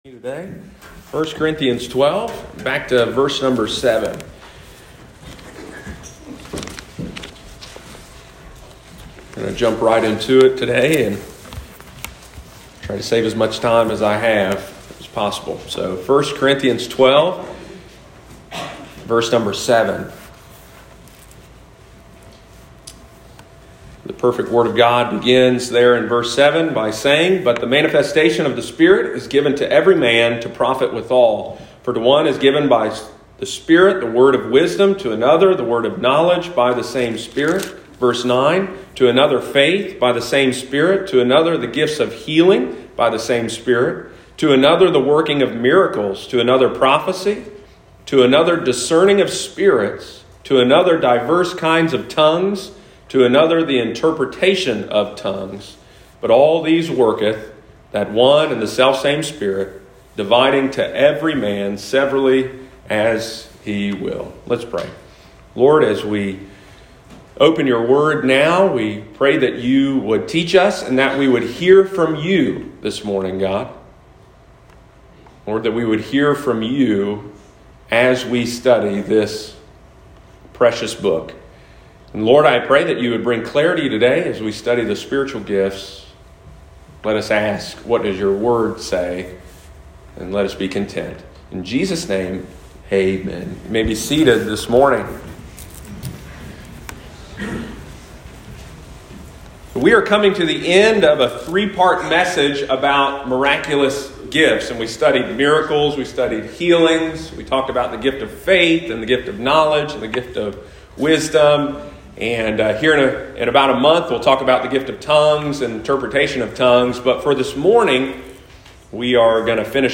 Are modern day “prophets” reliable and what is the gift of prophecy? In this sermon from our First Corinthians series we find biblical answers to these important questions.